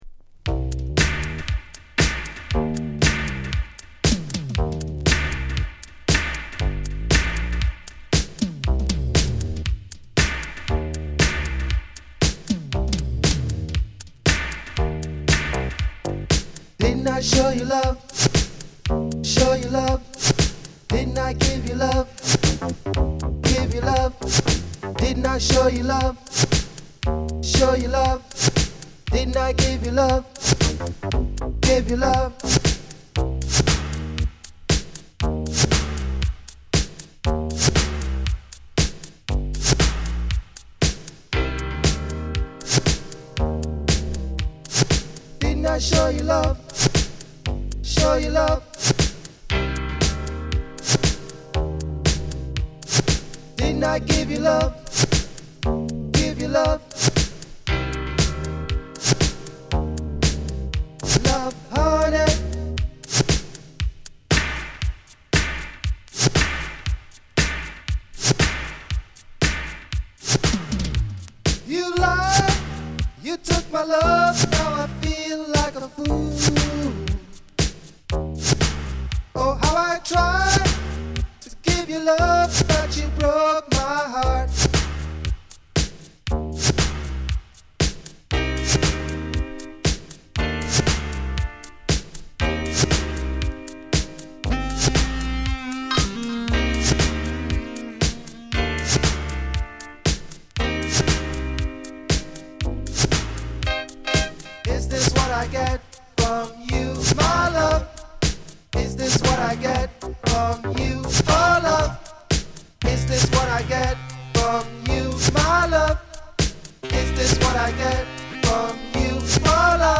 (Vocal)
(Instrumental)